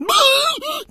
PigFear 03.wav